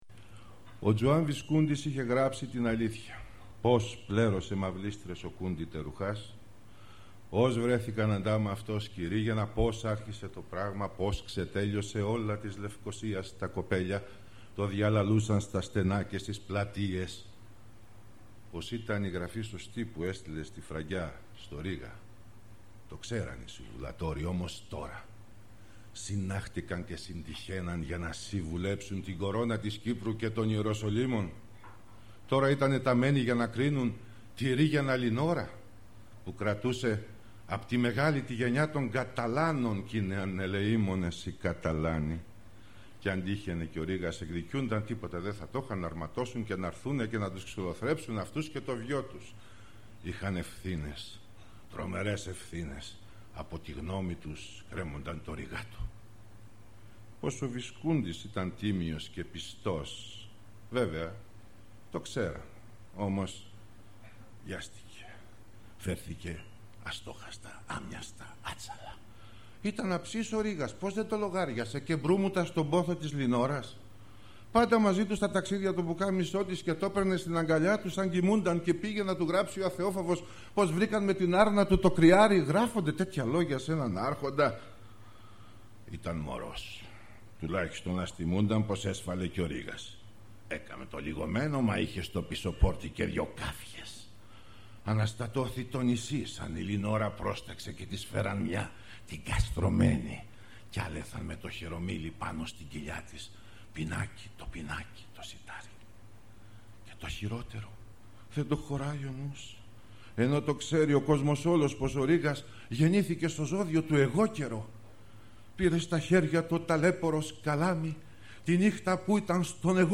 ������������ ���� ���������� - ������� ������� �������� - Seferis Reading